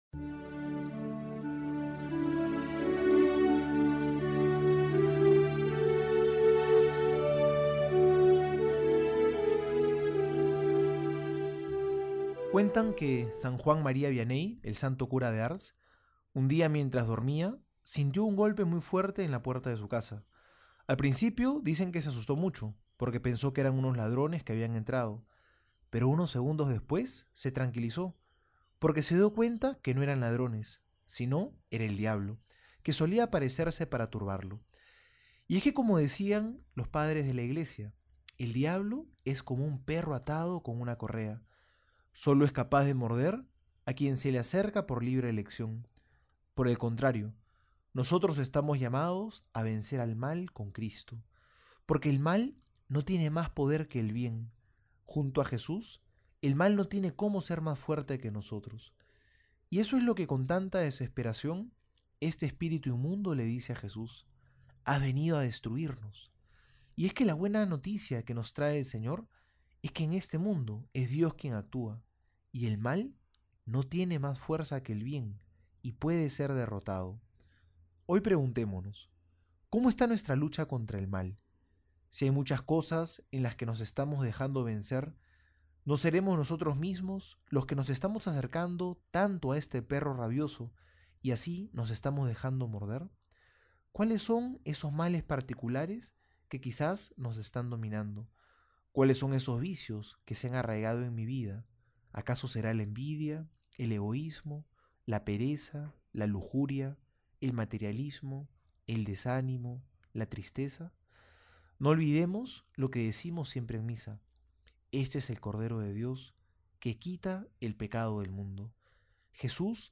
Homilía para hoy:
Martes homilia Lucas 4 31-37.mp3